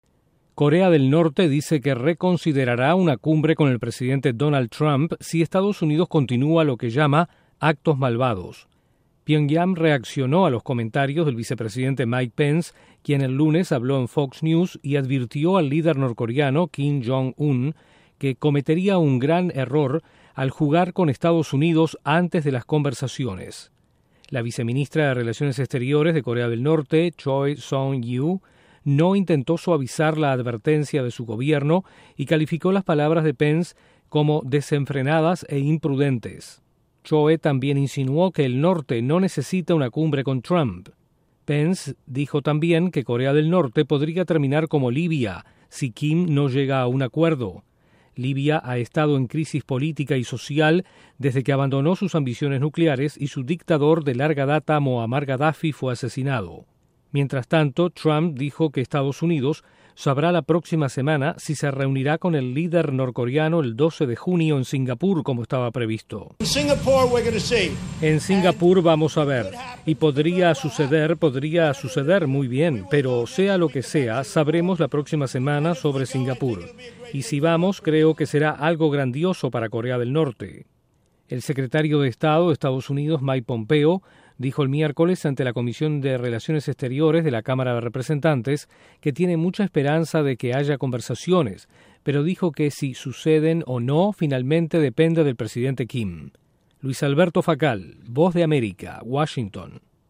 Corea del Norte dice que está reconsiderando la cumbre con EE.UU. tras palabras del vicepresidente Pence. Desde la Voz de América en Washington informa